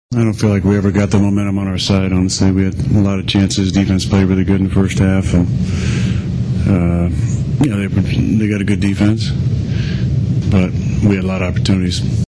Rodgers was frustrated that the Steelers offense couldn’t find its stride.